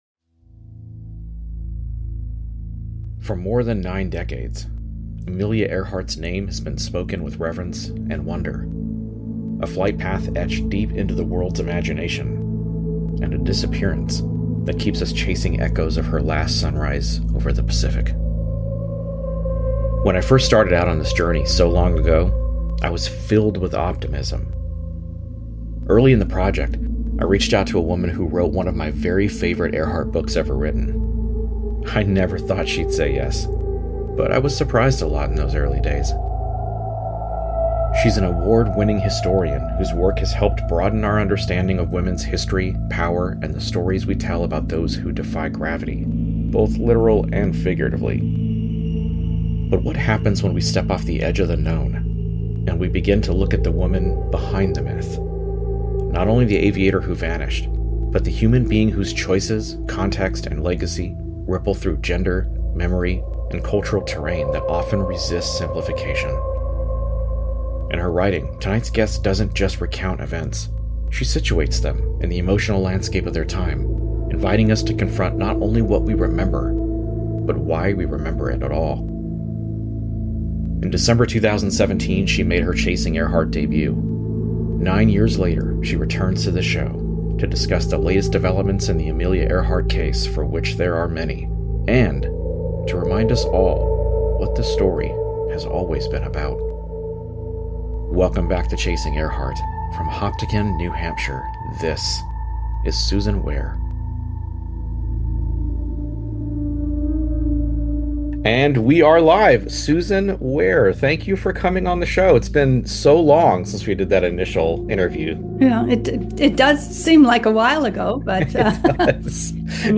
Revolution: A Conversation